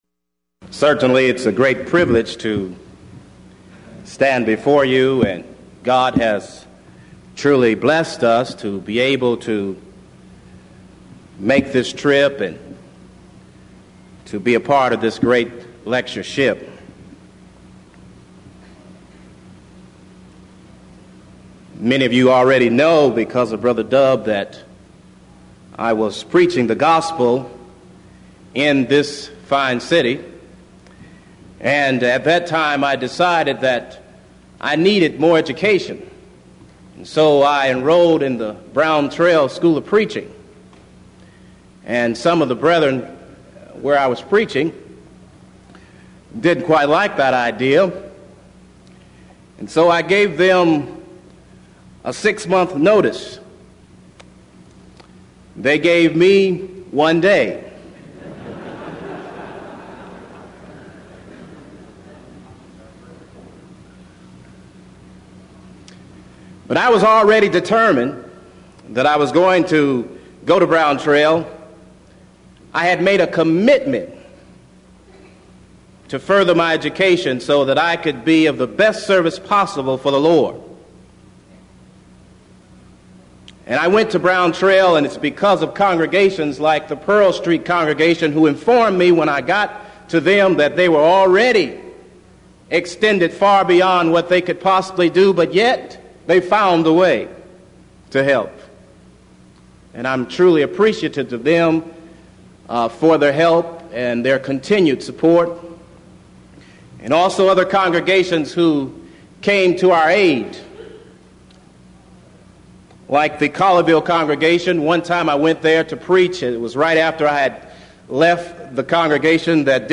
Event: 1989 Denton Lectures Theme/Title: Studies In The Book Of II Corinthians
lecture